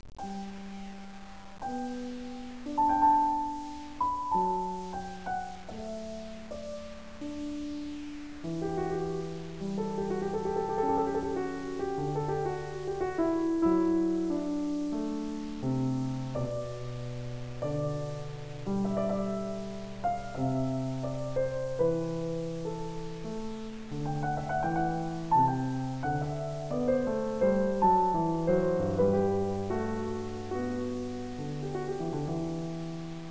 This display shows 33 seconds of the Aria from Bach's Goldberg Variations, played on a piano, in WAV format.
same file in 8-bit mono, and just for comparison, here is a link to a very good MIDI version for harpishord.